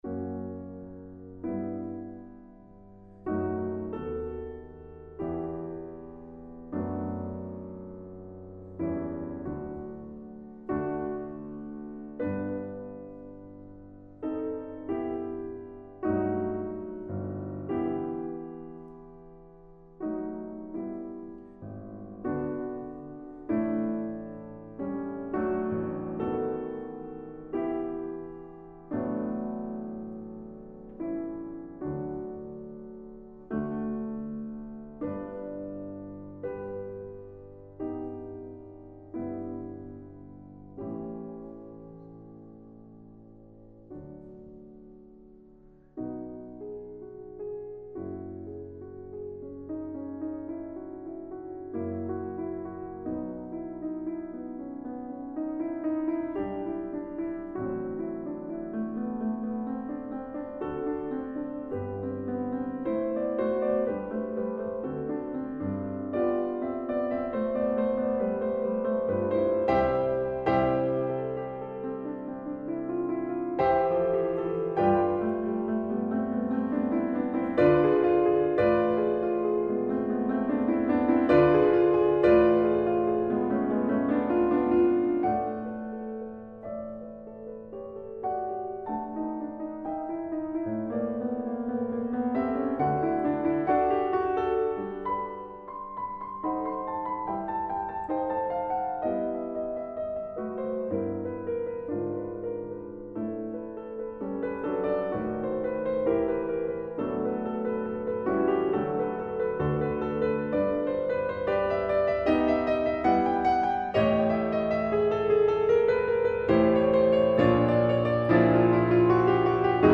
Romantic.